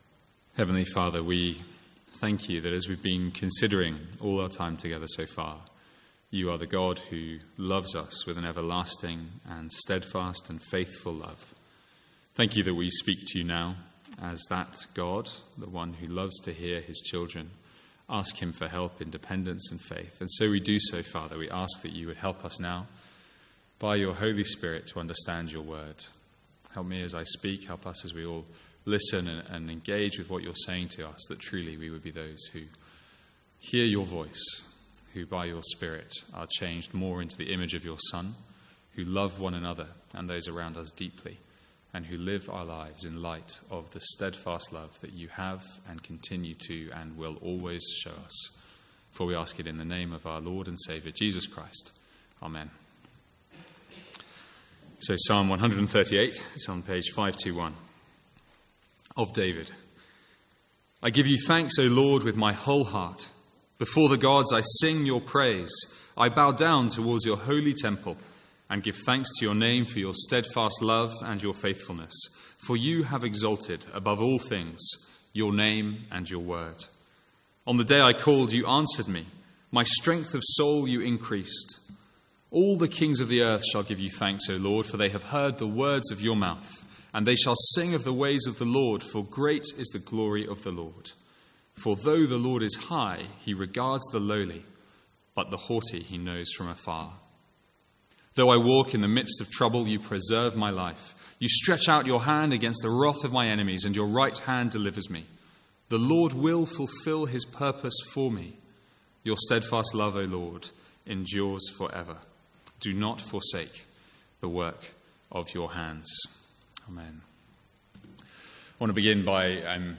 Sermons | St Andrews Free Church
From the Sunday evening series in the Psalms.